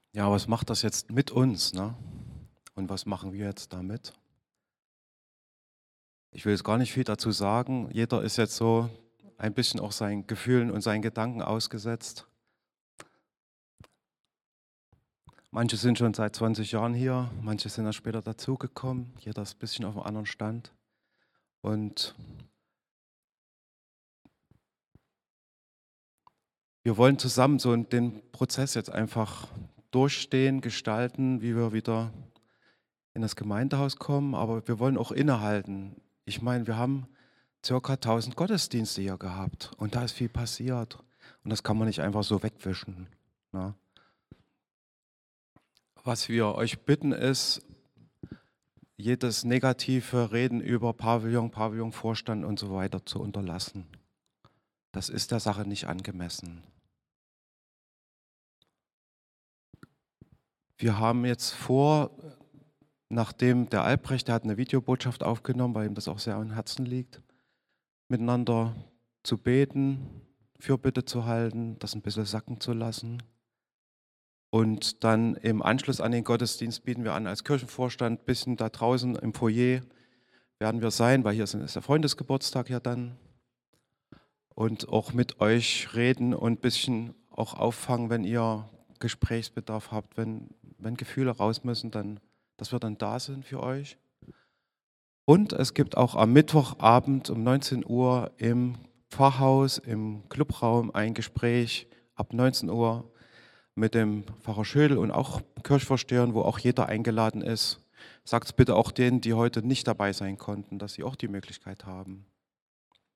Hier stellen wir Dir die Audiodateien aus dem Gottesdienst zur Verfügung.